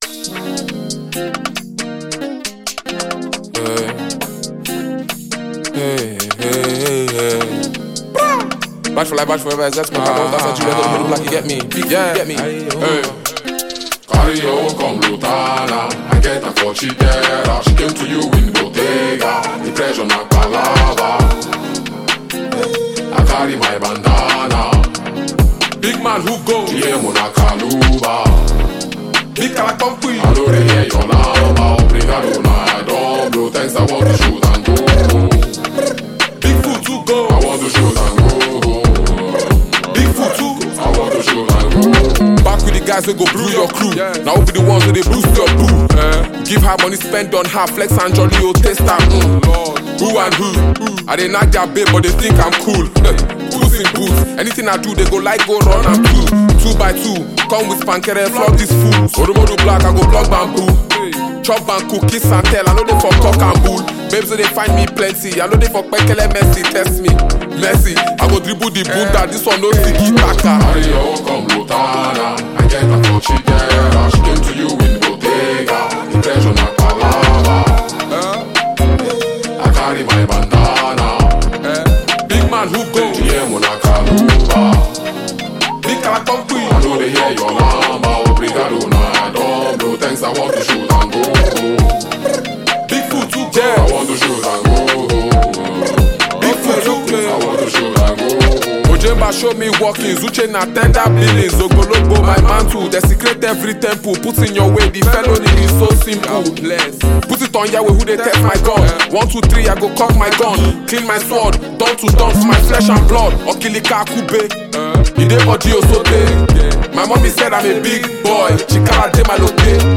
Phenomenon talented Nigerian rap artist and performer
new energizing song